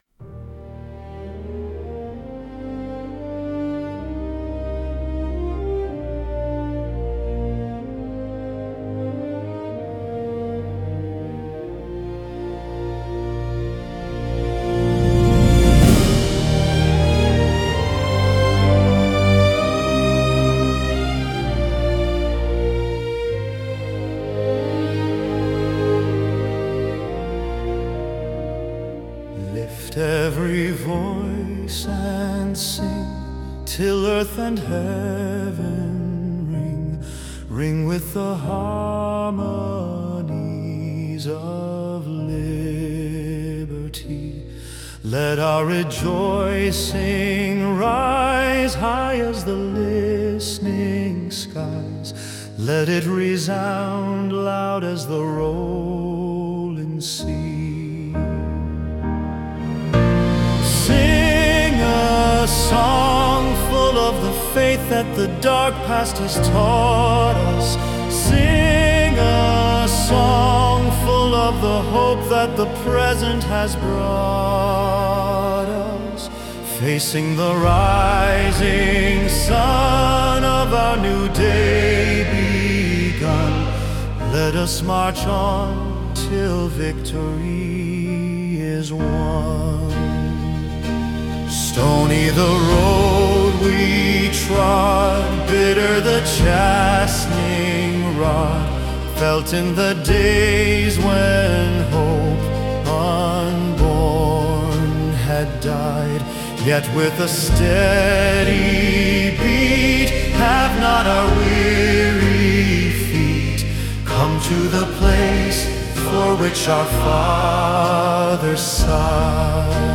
Vocals (Reference)
Use this to hear melody, entrances, and diction.
Lift-Every-Voice-and-Sing-Vocals.mp3